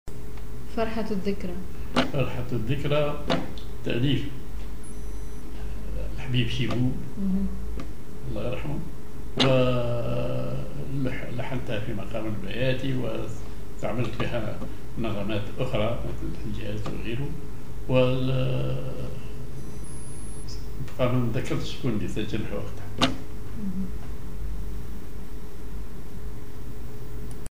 Maqam ar بياتي عشيران
genre أغنية